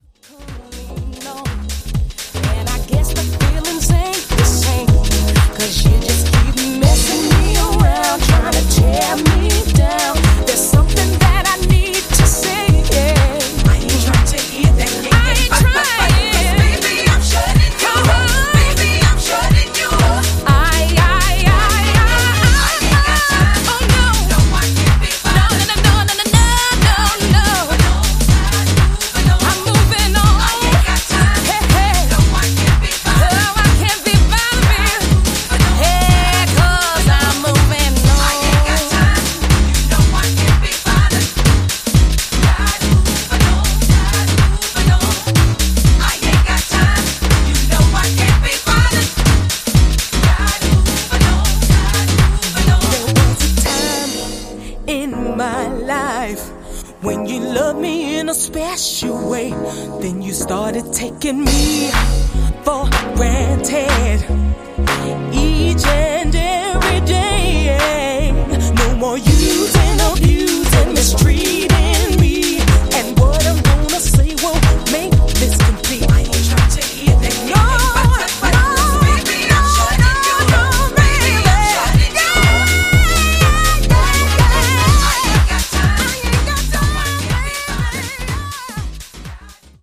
Four classic US house vocals.